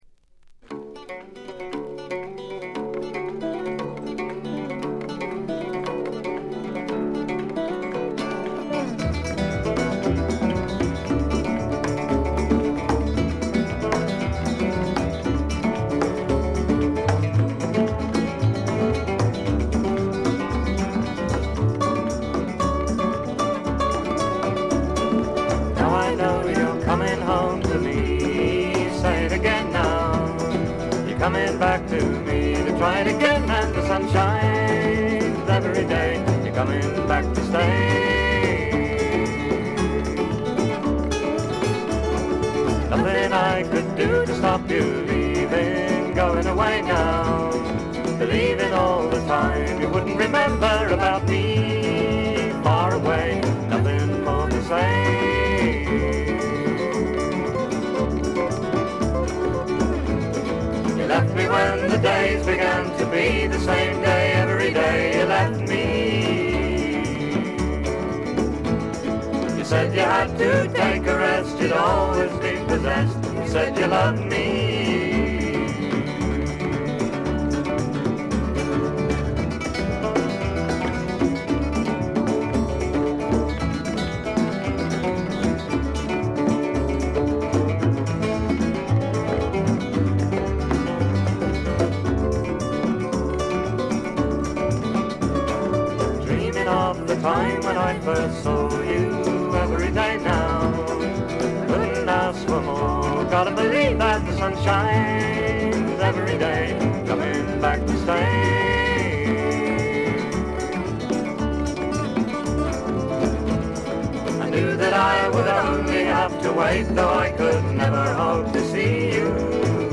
試聴曲は現品からの取り込み音源です。
Mandolin, Violin, Vocals
Percussion